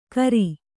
♪ kaṛi